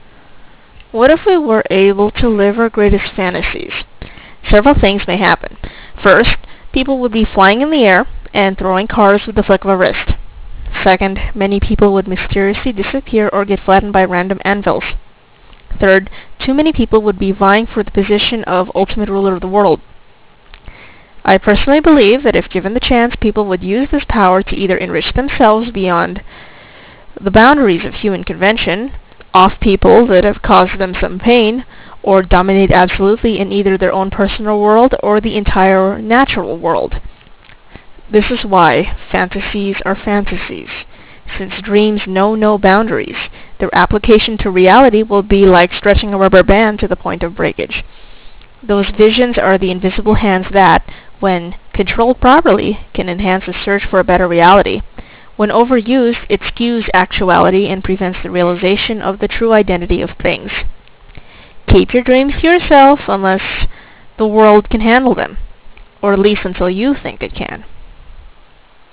Any distortion does not reflect the actual CD track. (It is only a consequence of compression.)